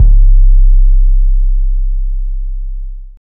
SouthSide 808 (10).wav